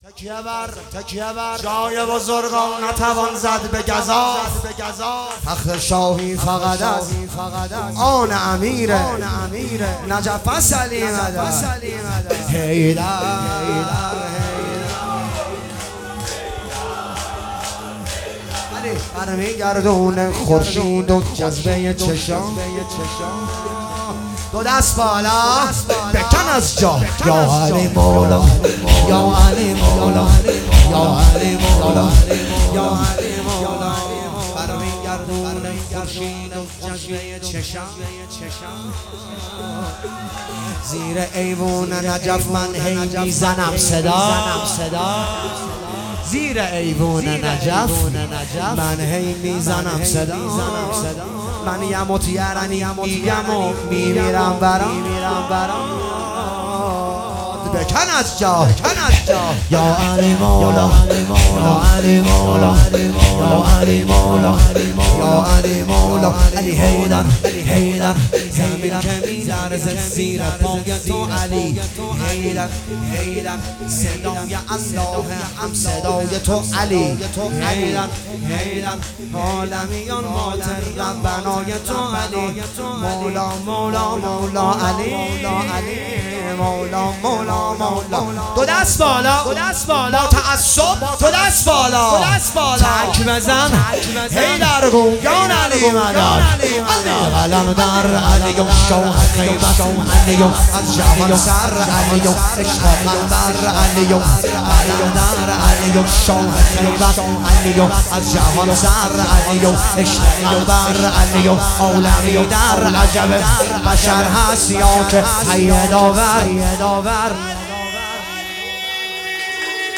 مداحی شور طوفانی
جلسه هفتگی اردیبهشت 1404